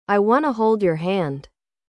1. Exemplo de Linking (Ligação):
• Análise: O “want to” vira “wanna”.